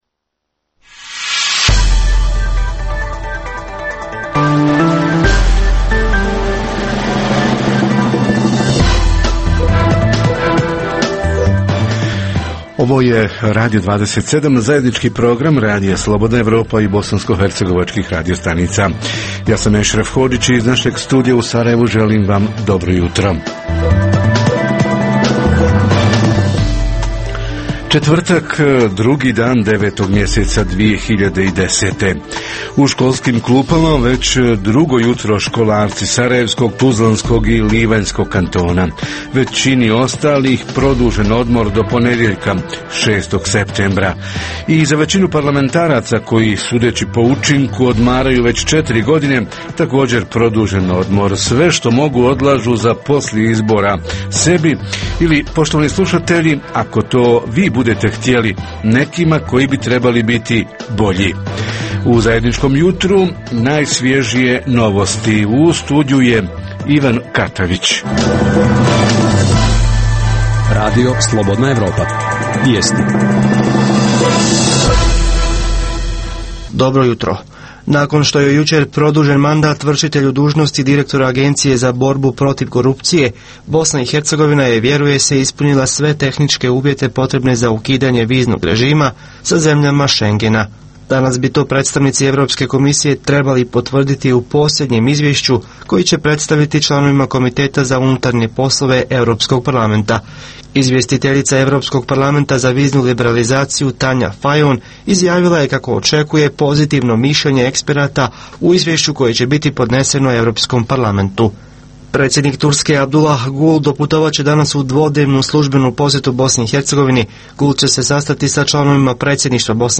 Protesti – kako i zašto najčešće protestiraju radnici, odnosno građani i koji se način pokazao najefikasnijim? O ovim pitanjima razgovaramo sa predstavicima sindikata, odnosno građana koji su protestirali ili štrajkovali – koliko su organizatori protesta ili štrajka poštovali zakonske odredbe o tome, koje odredbe nisu, zašto i koje su posljedice pretrpjeli. Reporteri iz cijele BiH javljaju o najaktuelnijim događajima u njihovim sredinama.